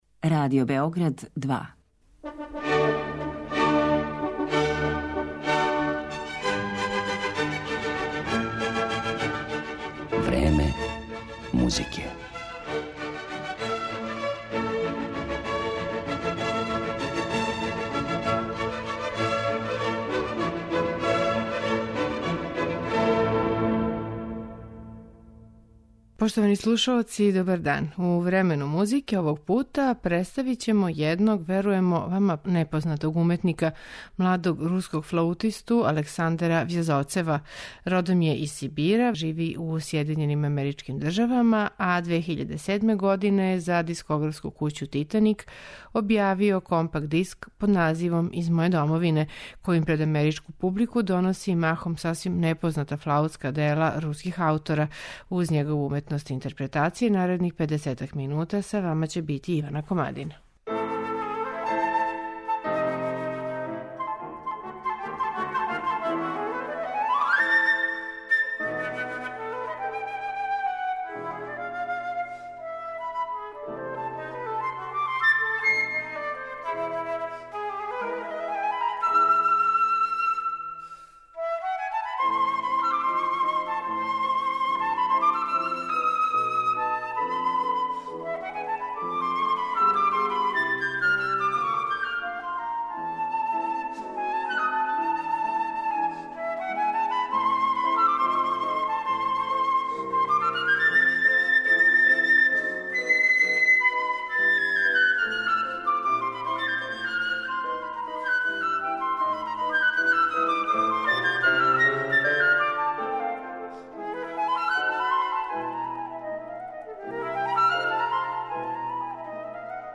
младог флаутисту
флаутска дела руских аутора